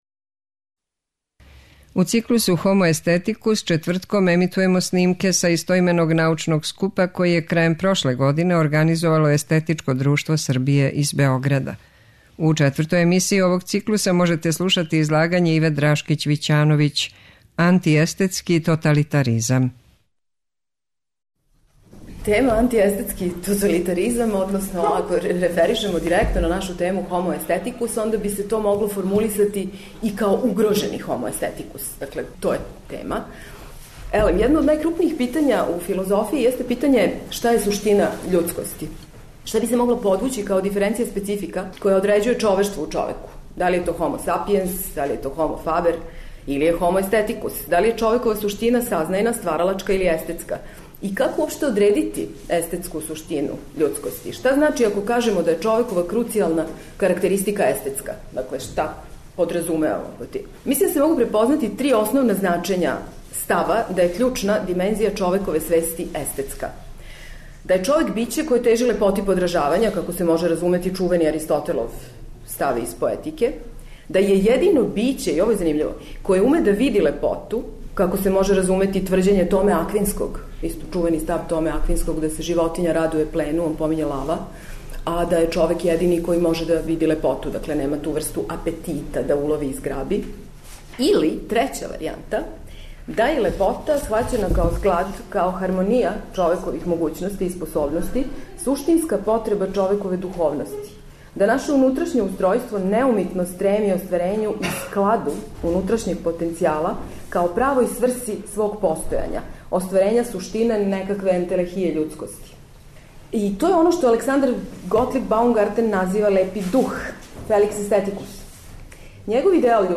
У циклусу HOMO AESTHETICUS четвртком ћемо емитовати снимке са истоименог научног скупа који је, у организацији Естетичког друштва Србије, одржан 22. и 23. децембра у Заводу за проучавање културног развитка у Београду.
Научни скупoви